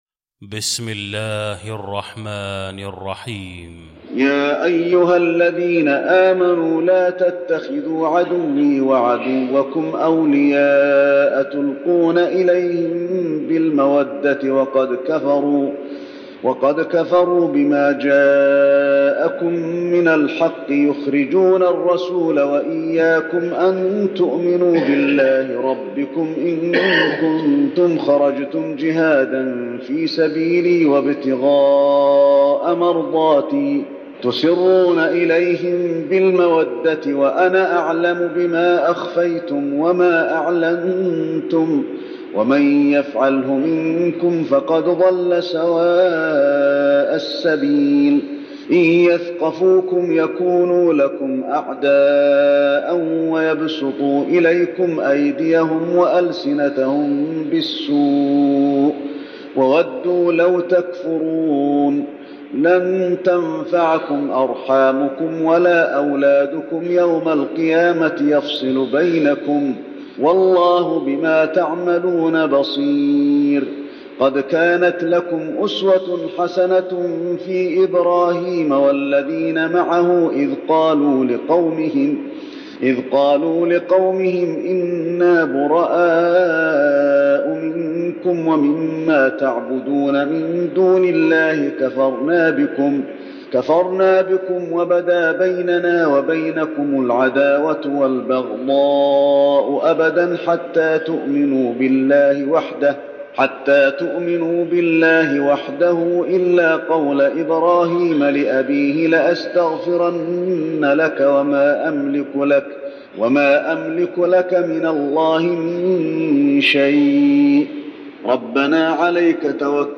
المكان: المسجد النبوي الممتحنة